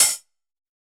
UHH_ElectroHatD_Hit-03.wav